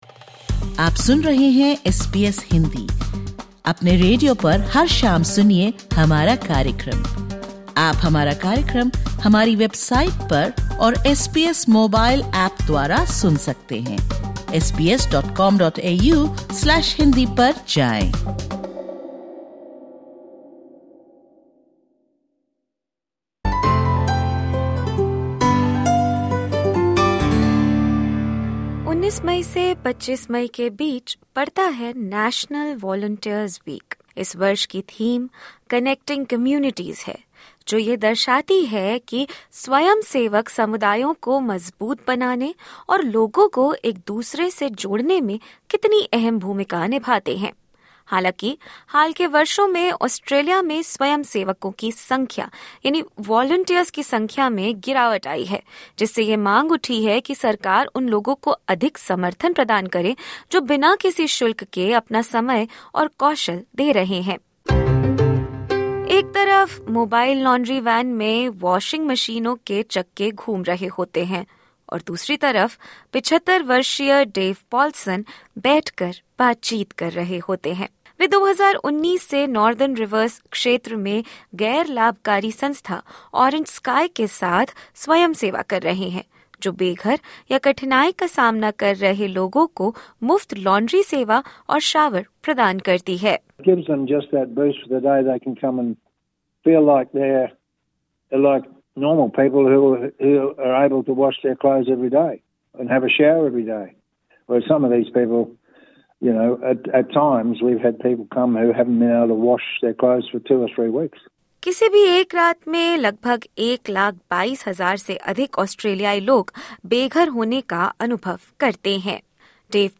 ऑस्ट्रेलिया के लगभग 60 लाख स्वयंसेवक बेघरों की मदद, आपदा राहत, और विकलांग युवाओं के सशक्तिकरण से समाज को जोड़ रहे हैं। लेकिन ऑस्ट्रेलिया में स्वयंसेवा दर में लगातार कमी देखी जा रही है जिसकी वजह प्रशिक्षण की कमी और वित्तीय चुनौतियों को बताया जा रहा है। इस अंश में सुनेंगे अलग अलग संस्थाओं के स्वयंसेवकों को, और समझेंगे उनके कार्य से जुड़ी चुनौतियों को भी।